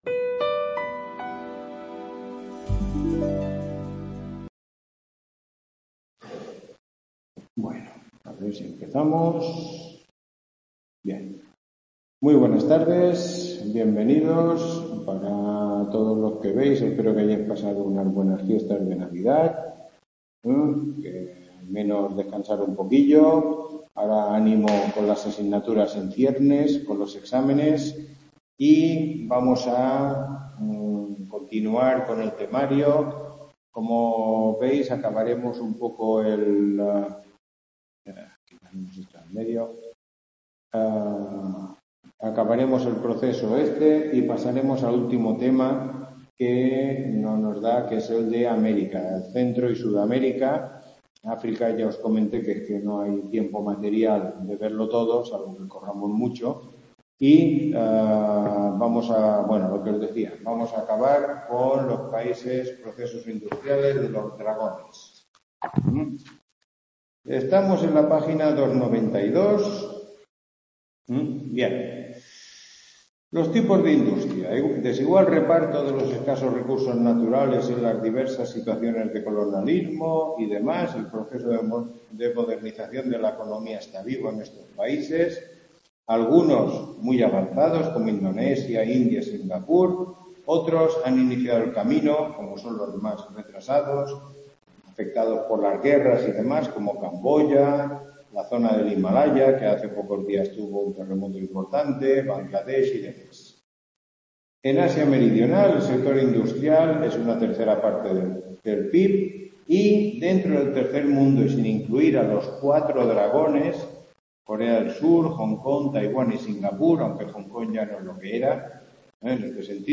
Tutoría 11